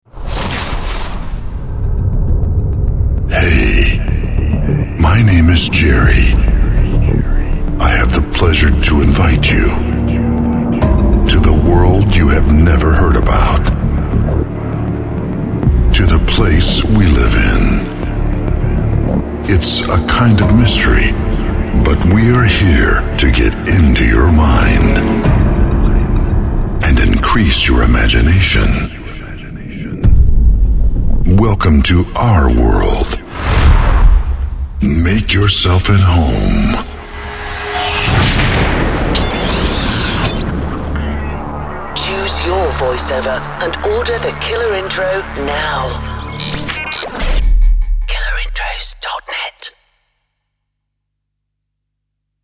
Voice-over is an inseparable element of our intros, creating their climate and character.
Deep, Dark